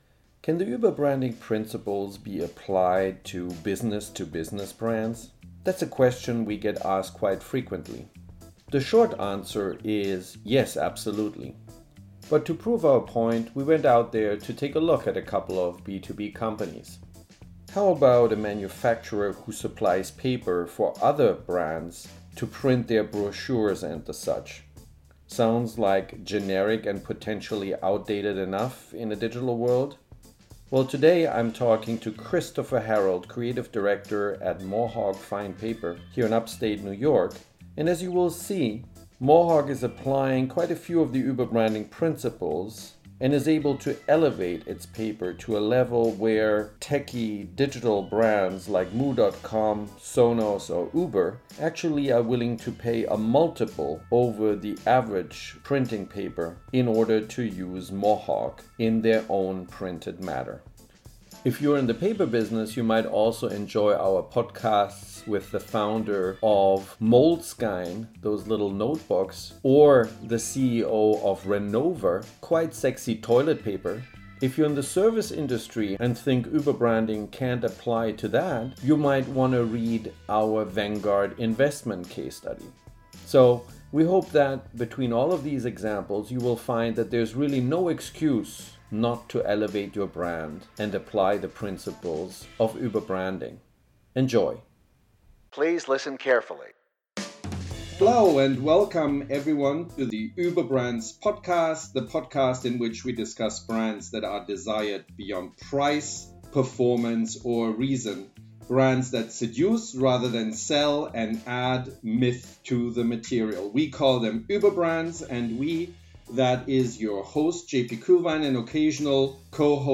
B2B Beyond The Material – Interview